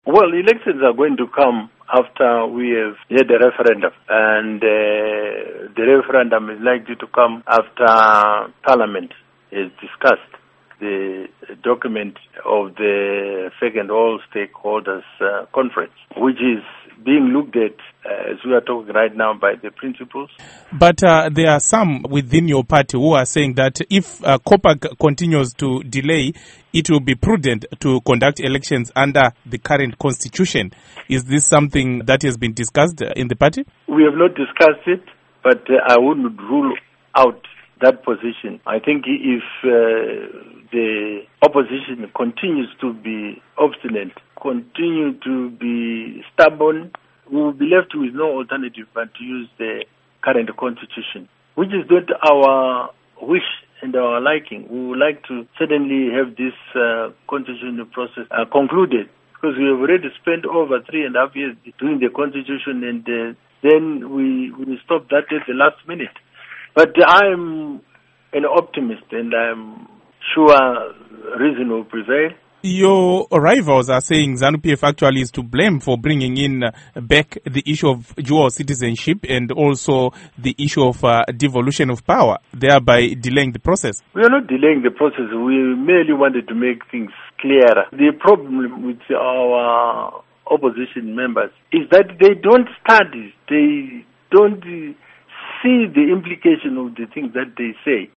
Embed share Interview With Rugare Gumbo by VOA Embed share The code has been copied to your clipboard.